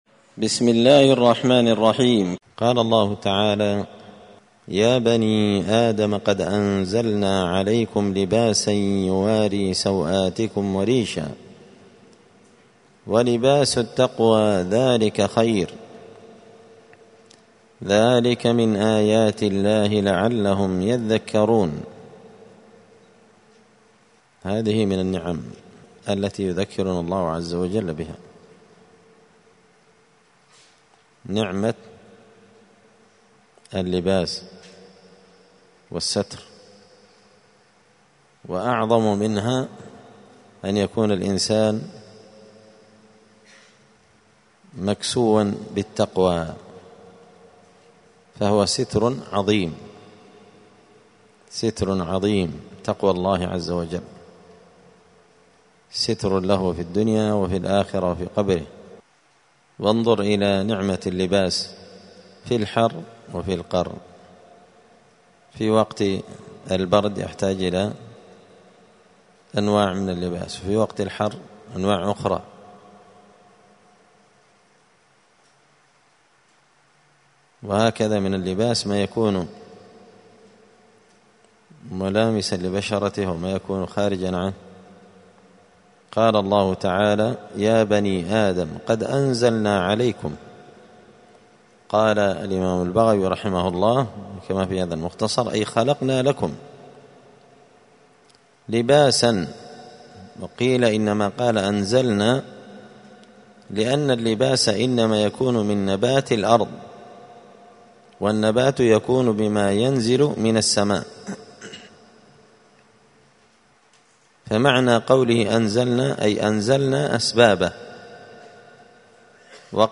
📌الدروس اليومية
دار الحديث السلفية بمسجد الفرقان بقشن المهرة اليمن